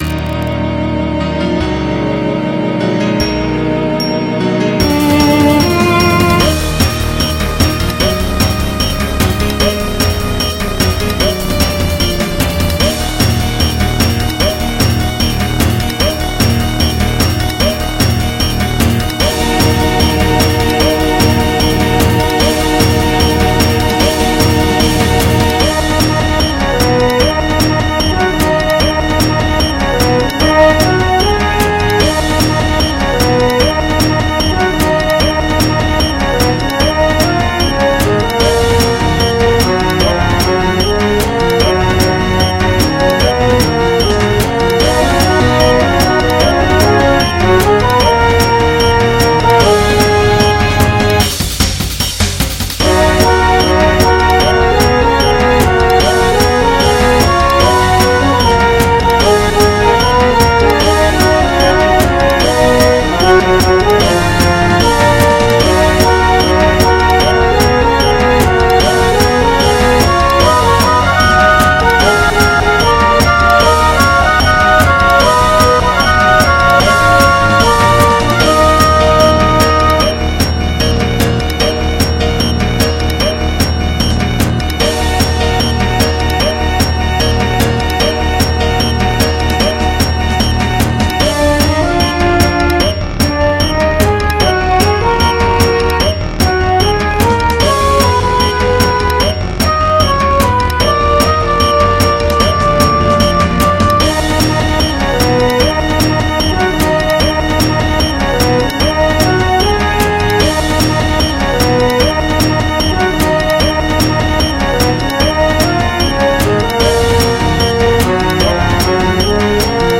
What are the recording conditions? MIDI 143.11 KB MP3 (Converted) 4.08 MB MIDI-XML Sheet Music